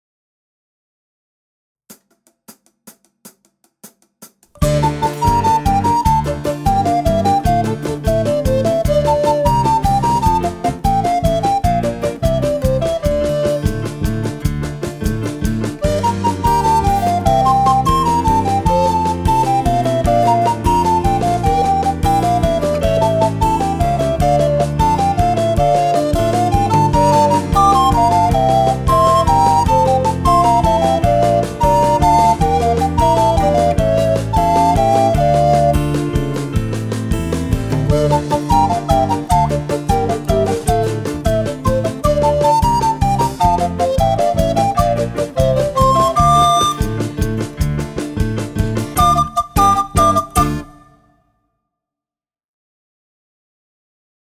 Gattung: Altblockflöte und Klavier
9 mittelschwere poppig-rockige Stücke
• Gesamteinspielung der Stücke (mit Band)